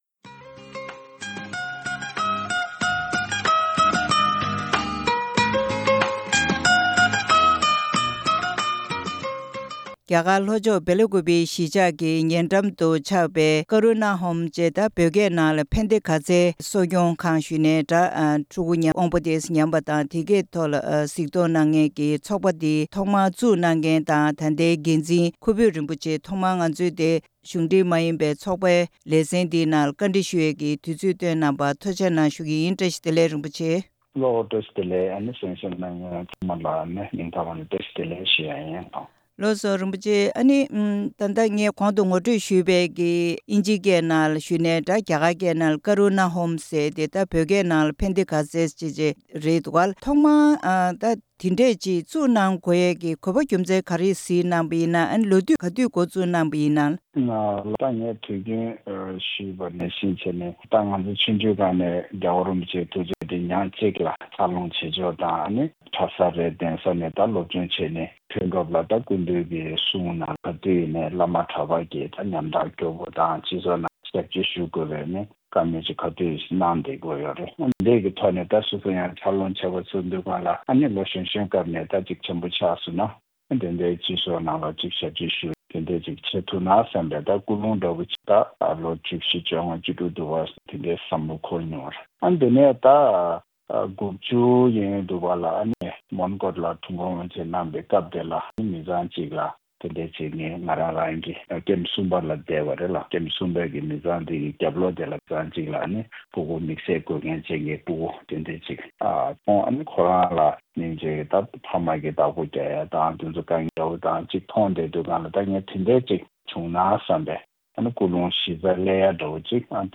བཀའ་འདྲི་ཞུས་པ་ཞིག་གསན་གནང་གི་རེད་